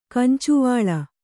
♪ kancuvāḷa